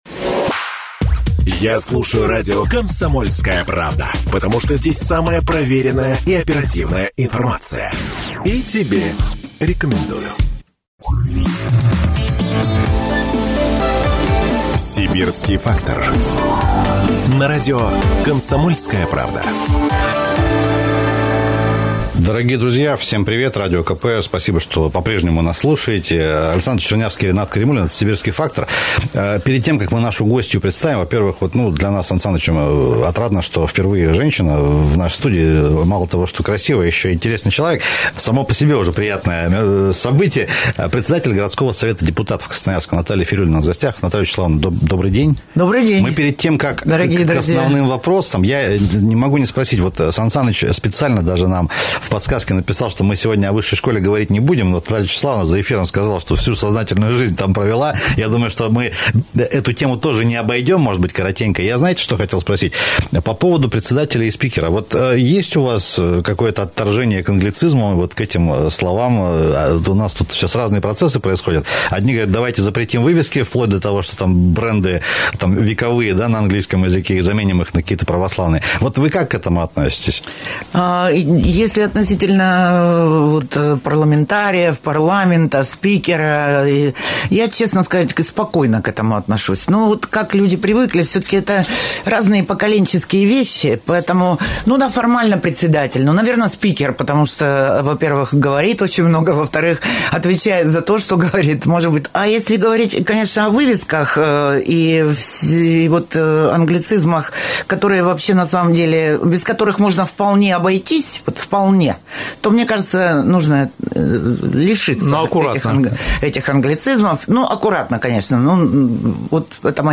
О рычагах оздоровления экологической ситуации, градостроительной политике, транспортном будущем Красноярска, подготовке к 400-летию краевого центра и будущем сибирских городов в нашей постоянной программе рассказала в прямом эфире радио «КП — Красноярск» председатель городского Совета Красноярска Наталия Фирюлина.